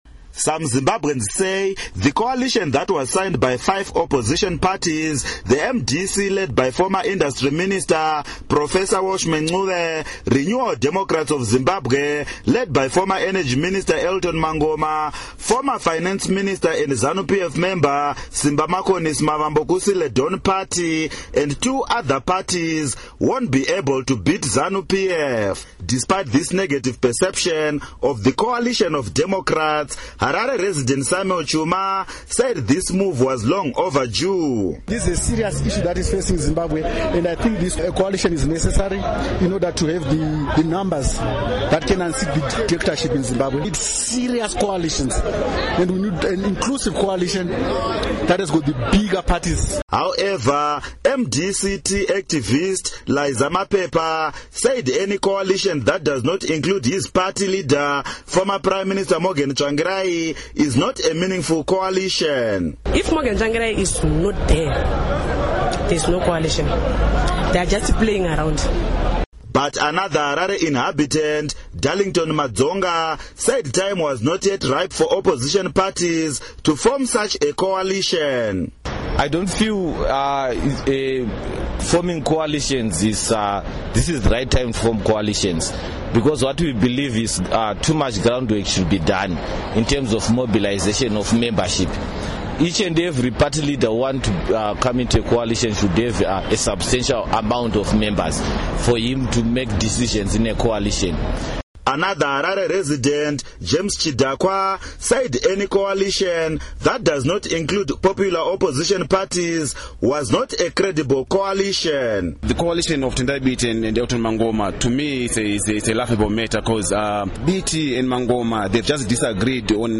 Report on Opposition Parties' Coalition